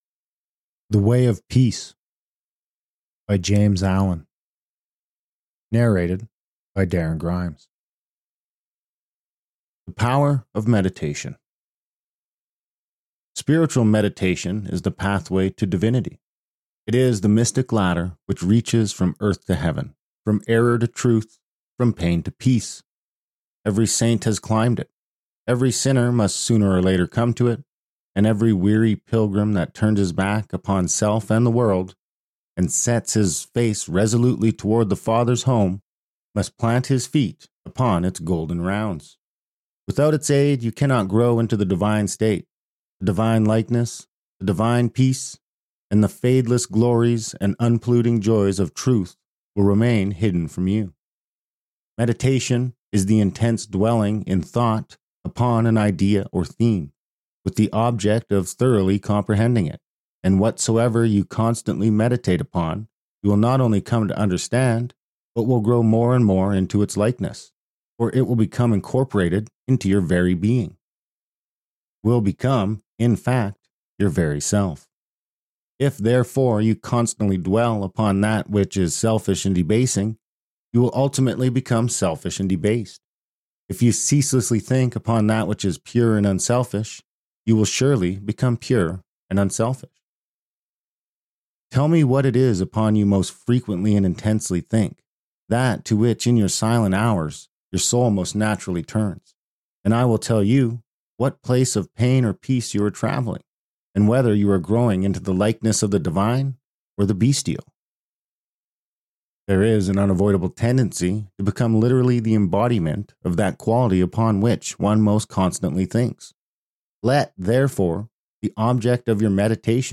Podcast (audiobooks)